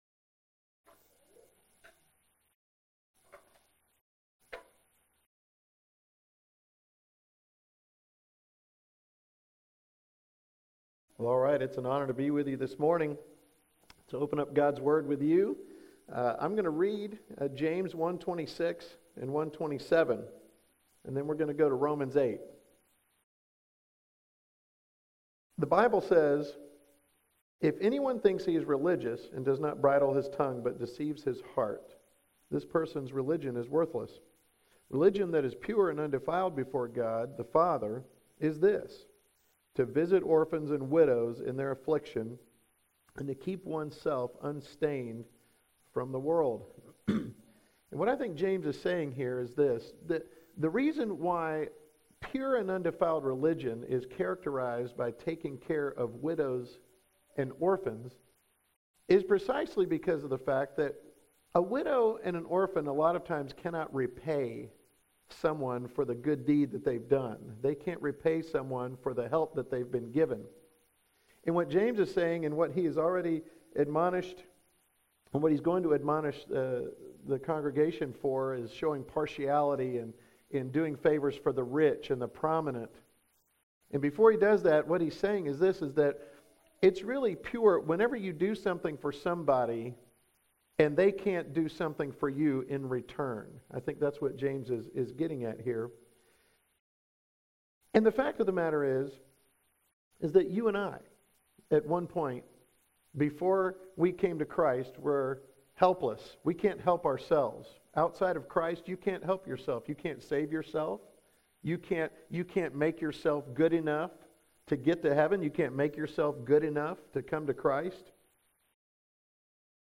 September 11, 2011 AM Worship